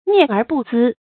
涅而不淄 注音： ㄋㄧㄝ ˋ ㄦˊ ㄅㄨˋ ㄗㄧ 讀音讀法： 意思解釋： 見「涅而不緇」。